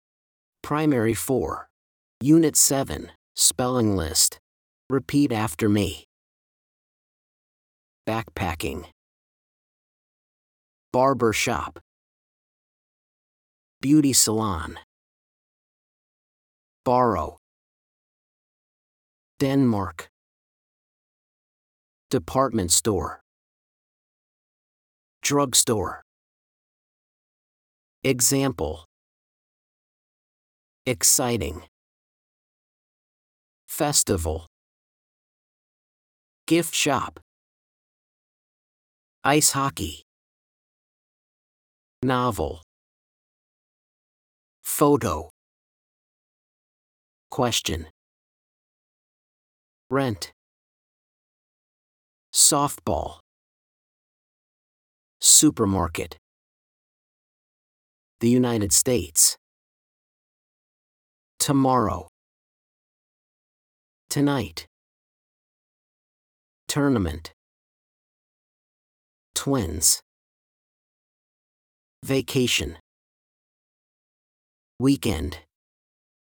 These are the words on the spelling list. Listen and repeat after the teacher: